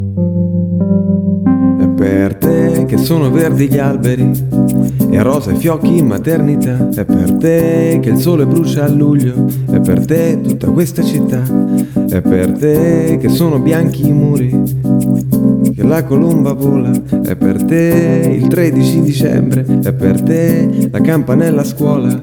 первый куплет: